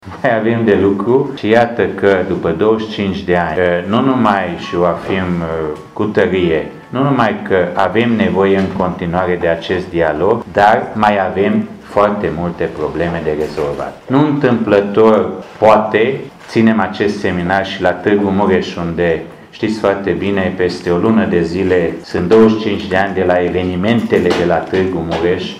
Președintele Fundației Culturale dr. Bernady Gyorgy, deputatul Borbely Laszlo.